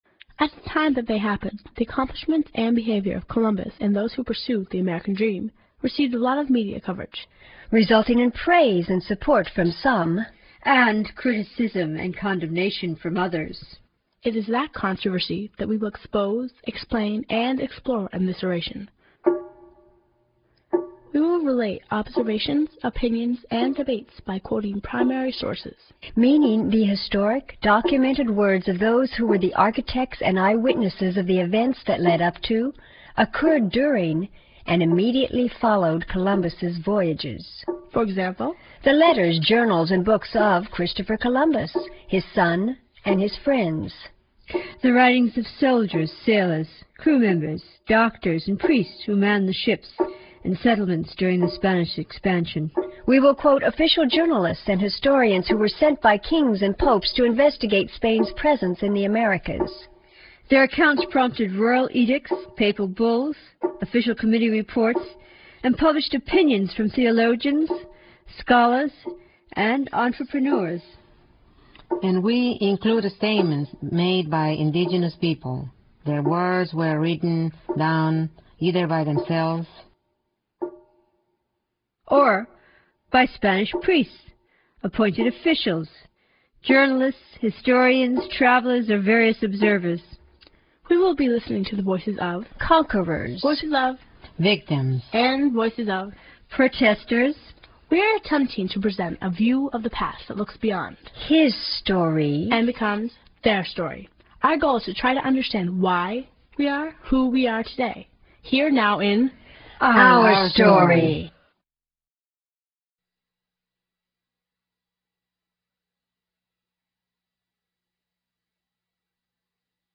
All of these documented materials are dramatically presented with historically relevant music, and by four Speakers: